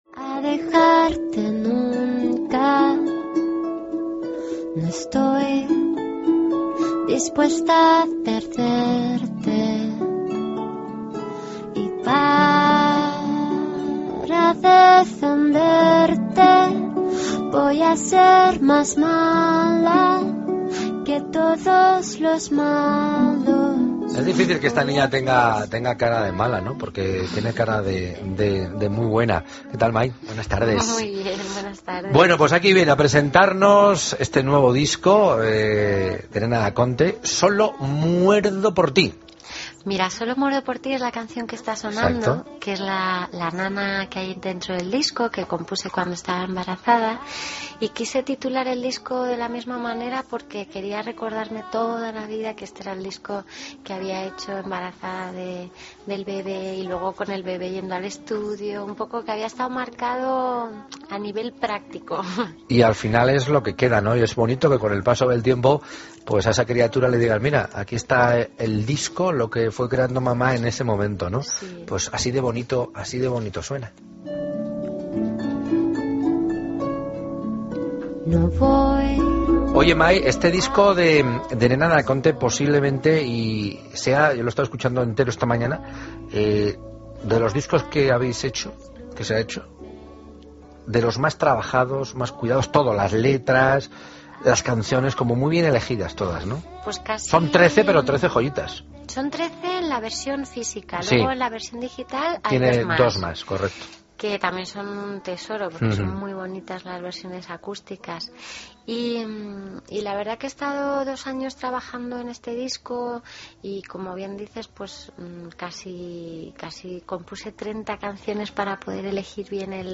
Escucha la entrevista a Nena Daconte en La Tarde.